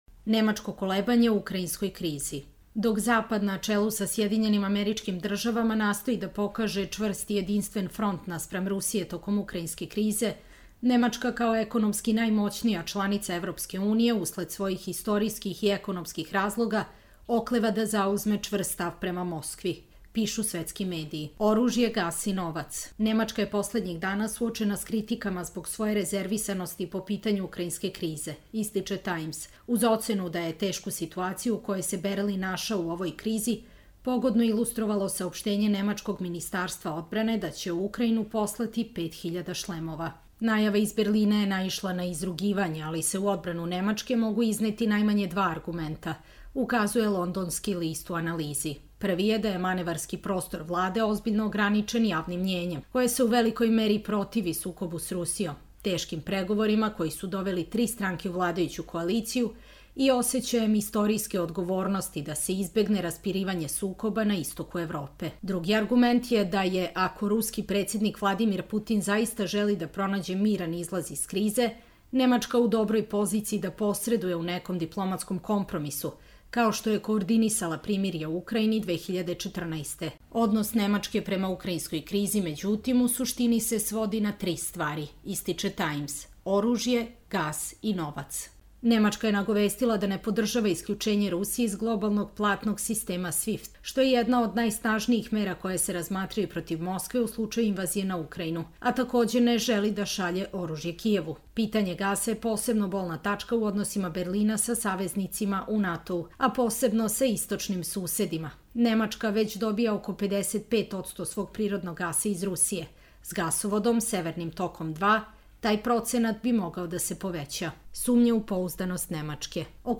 Čitamo vam: Nemačko kolebanje u ukrajinskoj krizi